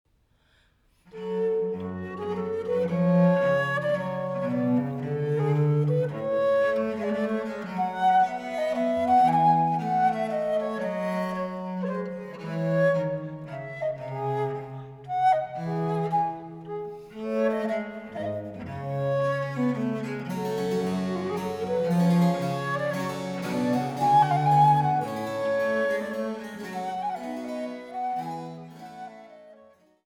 Traversflöte
Cembalo